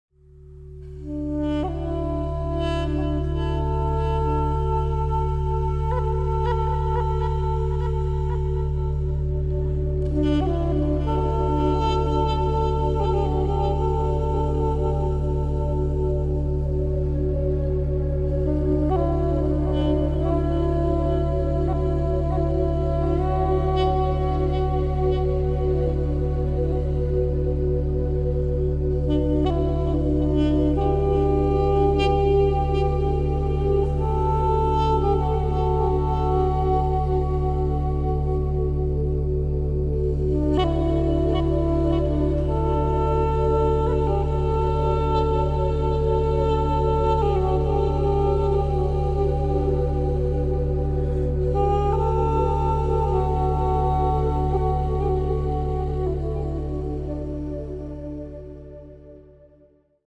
Feel the calm at 72 Hz – the frequency that soothes the nervous system and nurtures emotional balance.